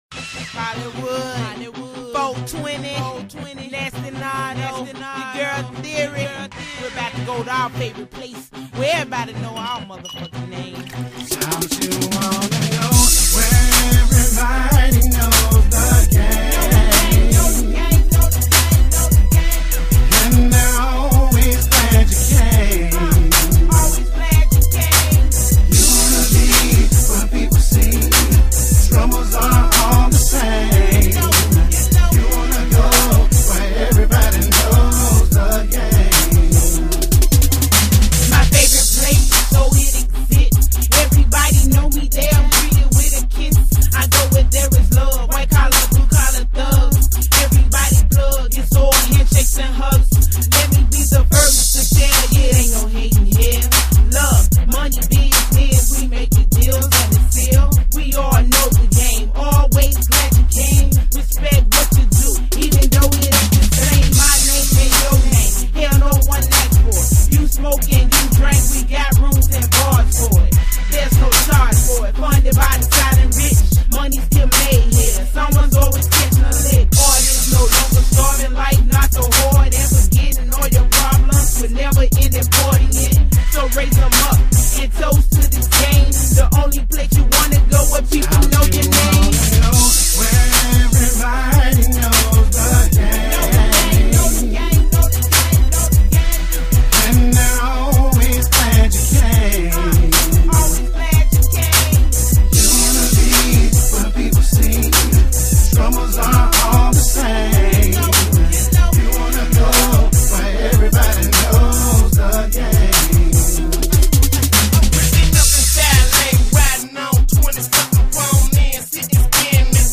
Genre: Memphis Rap.